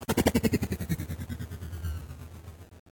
warp_out.ogg